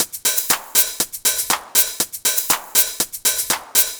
120_HH+shaker_1.wav